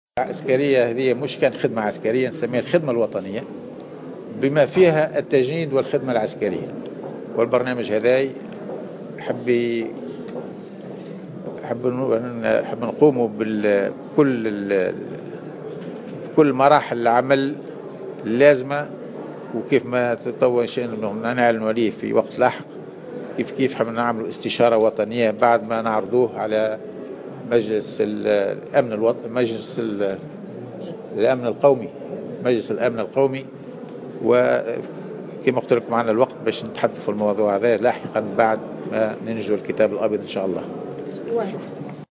أكد وزير الدفاع عبد الكريم الزبيدي في تصريح لمراسل الجوهرة "اف ام" اليوم الثلاثاء 7 نوفمبر 2017 على هامش اشرافه على افتتاح الدورة 35 لمعهد الدفاع الوطني في برطال حيدر بمنوبة أن الوزارة لديها برنامجا متكاملا للتجنيد والخدمة العسكرية .